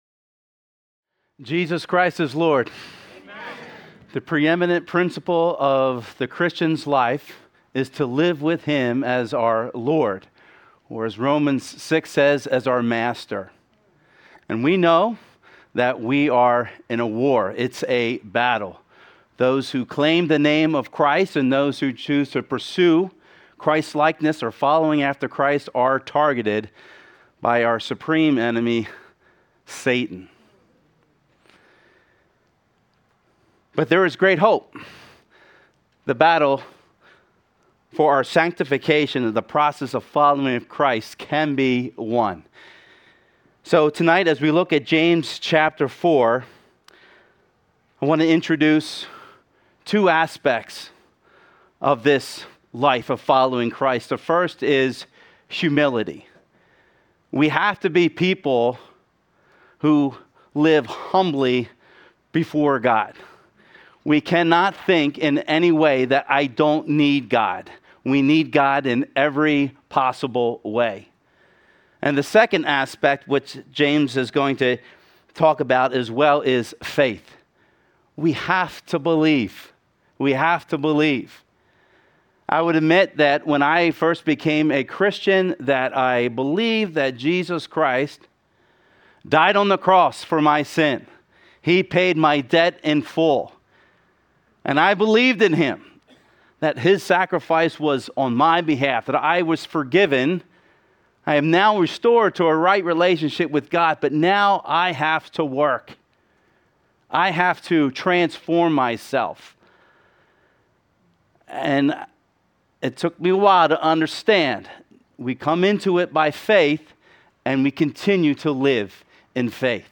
Sermons | Calvary Chapel